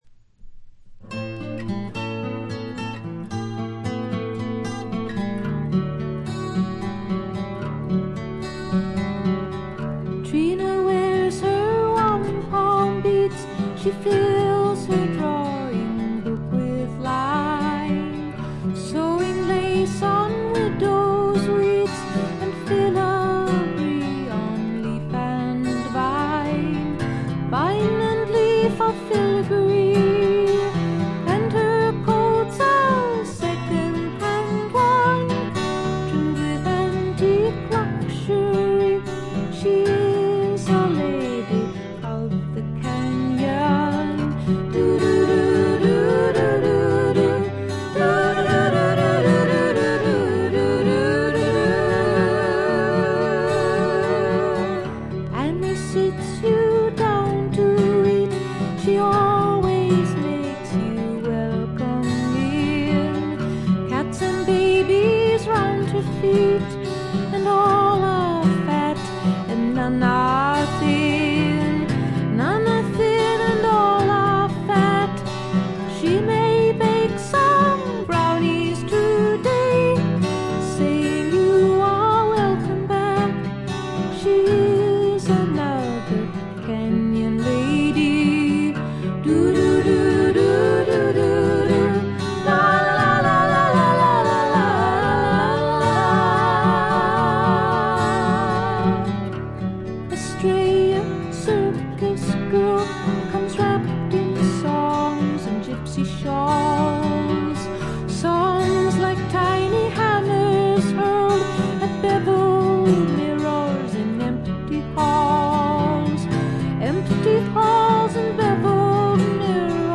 軽微なバックグラウンドノイズ、チリプチ。散発的なプツ音少し。
試聴曲は現品からの取り込み音源です。